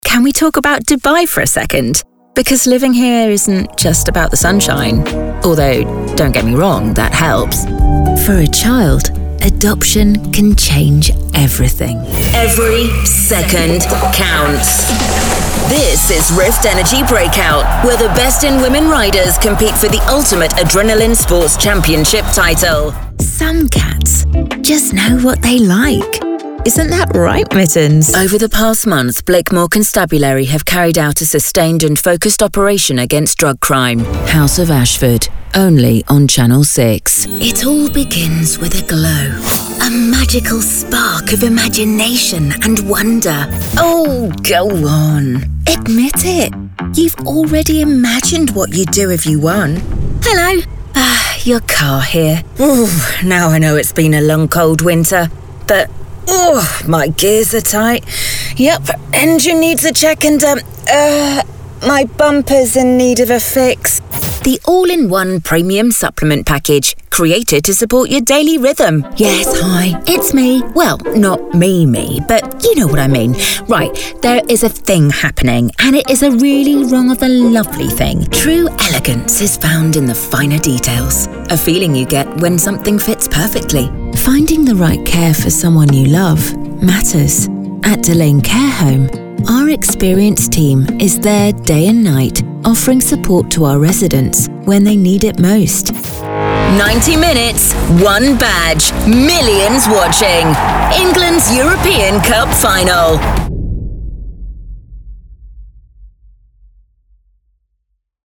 Commercial Reel - 2026
accented, accented English, british, english-showcase
accented, accented English, anti-announcer, british, caring, concerned, confessional, conversational, genuine, real, serious, sincere, soft-spoken, storyteller
accented, accented English, announcer, british, compelling, cool, english-showcase, impression, raspy, sexy, smooth
accented English, authoritative, british, classy, confident, corporate, english-showcase, informative, long form, neutral
accented, accented English, announcer, british, classy, compelling, confident, cool, english-showcase, edgy, imaging, impression, sexy, sophisticated